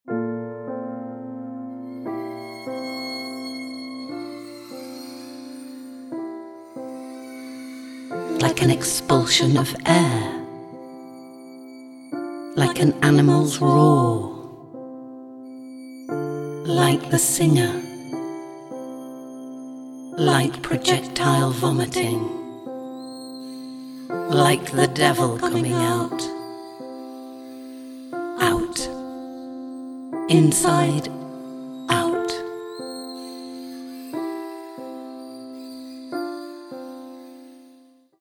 sound & editing
writing & voice